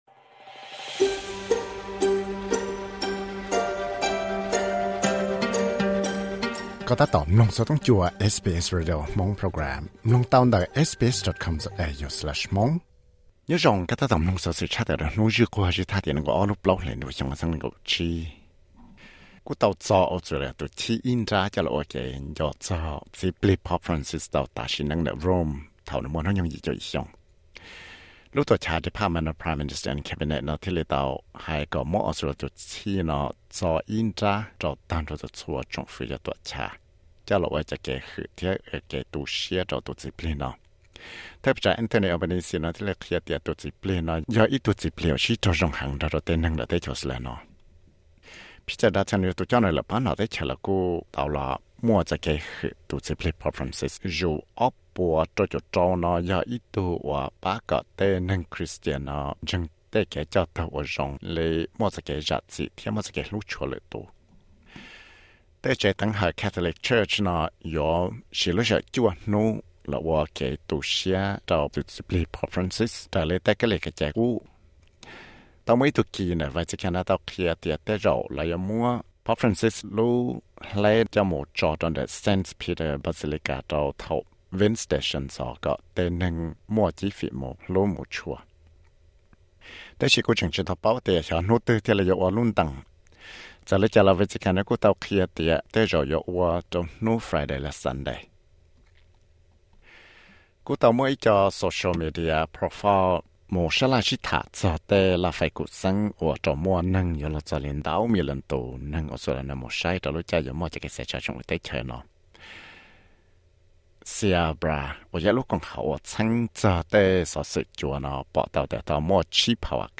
Xov xwm luv Credit